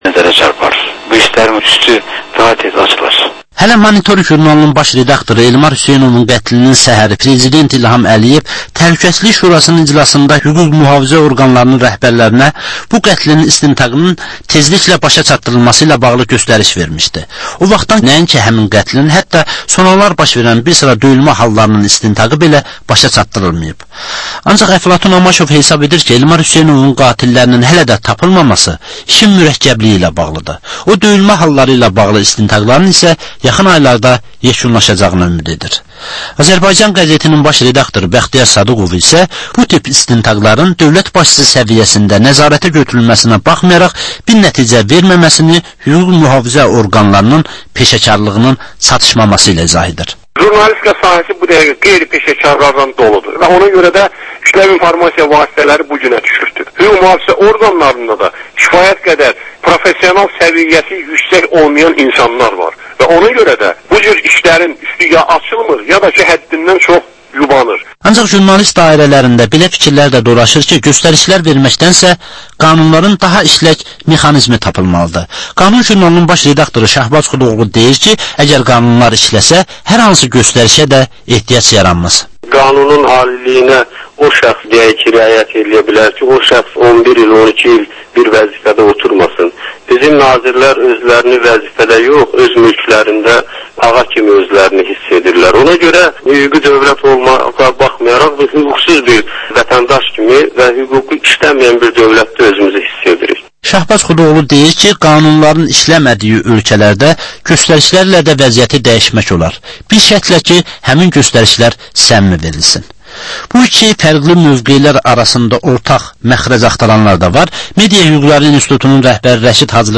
Aktual mövzu barədə canlı dəyirmi masa söhbəti.